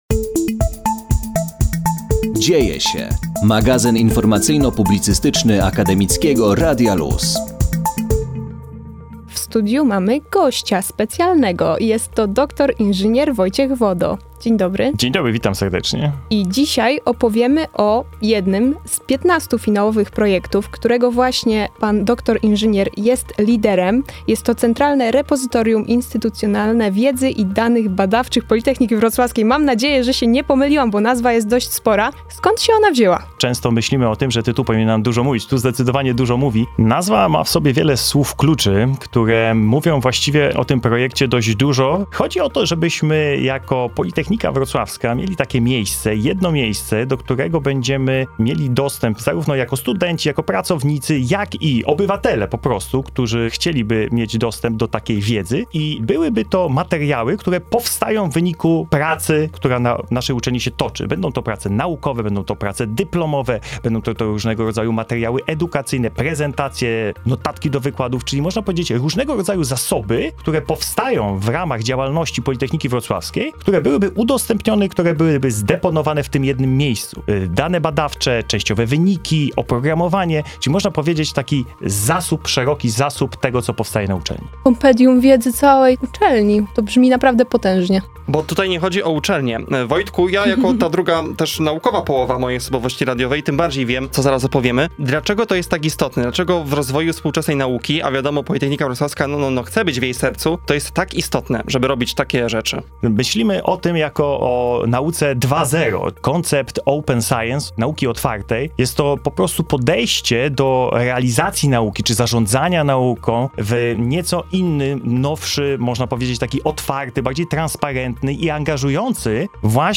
POLYTECHNICA NOVA - wywiady z laureatami pierwszego etapu konkursu - Radio LUZ
Siódmego kwietnia wystartował drugi etap trzeciej edycji konkursu Polytechnica Nova na Politechnice Wrocławskiej. Wraz z nim ruszył nasz cykl rozmów z przedstawicielami zakwalifikowanych wniosków na antenie Akademickiego Radia Luz.